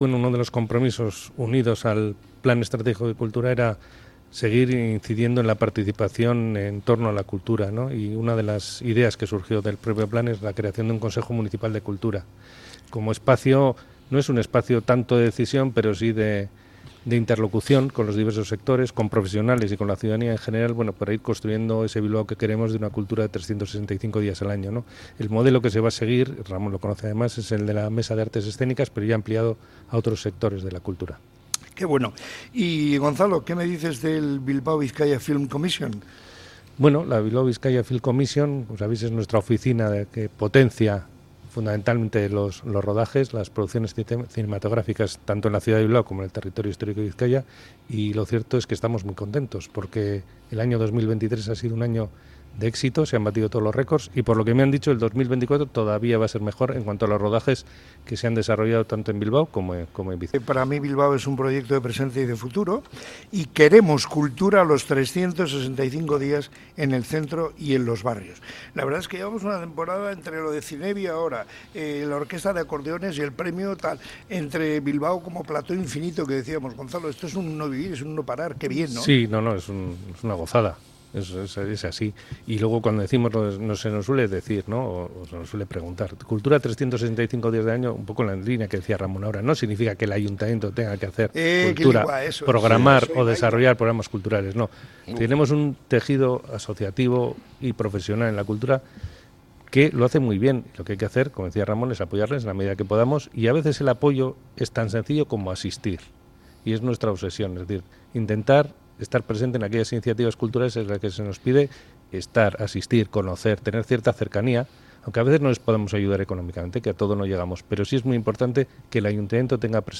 El concejal de Cultura del Ayuntamiento de Bilbao nos detalla algunos de los planes futuros